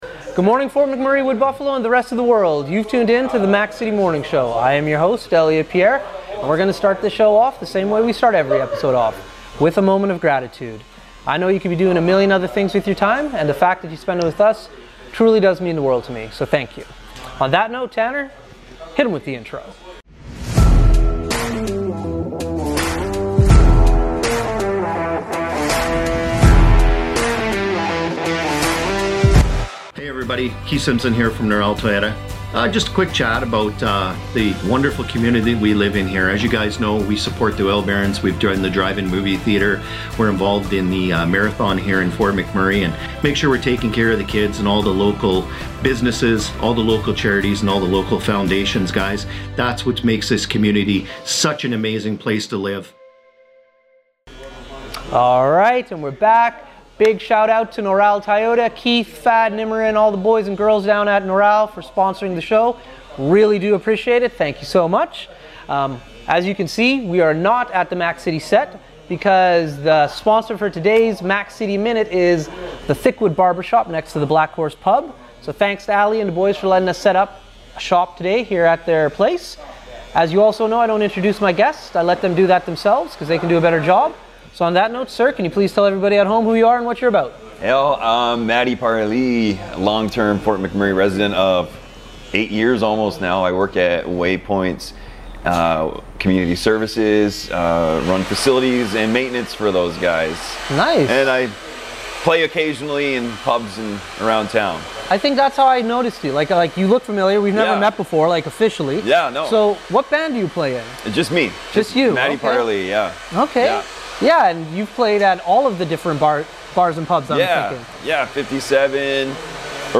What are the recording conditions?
on Location at Thickwood Barbershop